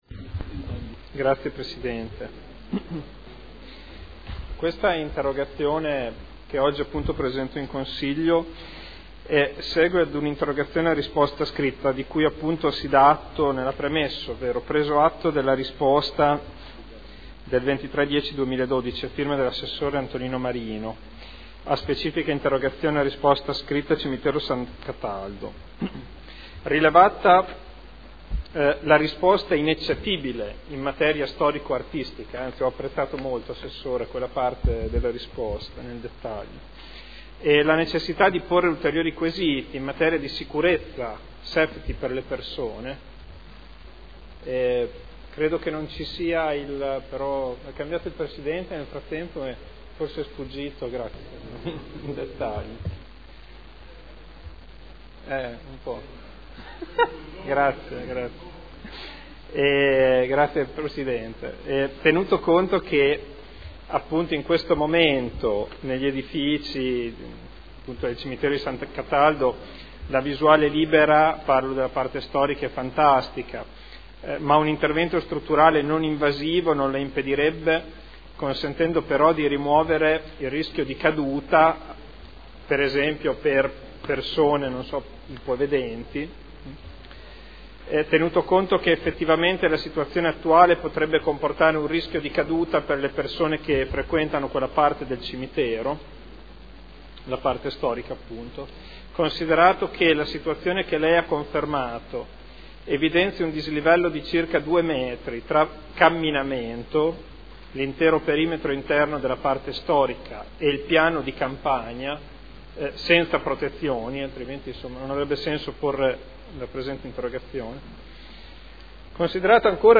Interrogazione del consigliere Ricci (Sinistra per Modena) avente per oggetto: “Cimitero S. Cataldo”